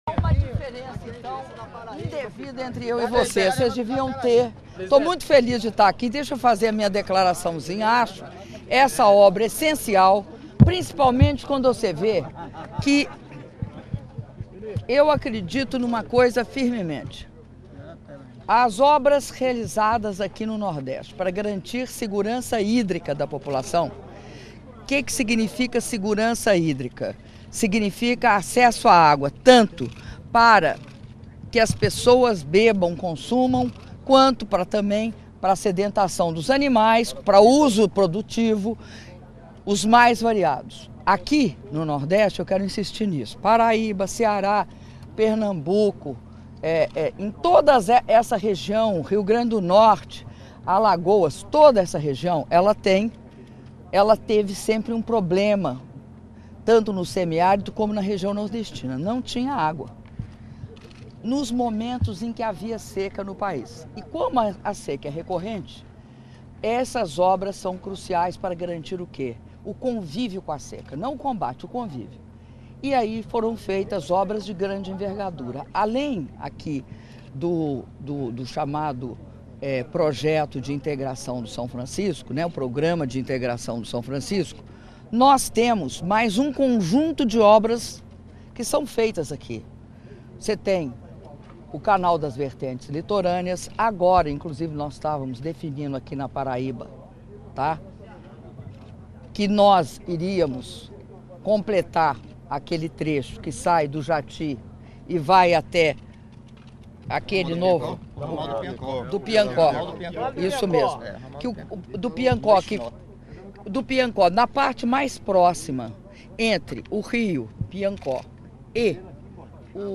Áudio da entrevista coletiva concedida pela Presidenta da República, Dilma Rousseff, após visita ao Túnel Cuncas II, do Projeto de Integração do Rio São Francisco – PISF - São José de Piranhas/PB (07min)